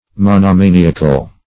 Monomaniac \Mon`oma"ni*ac\, Monomaniacal \Mon`oma"ni*a*cal\, a.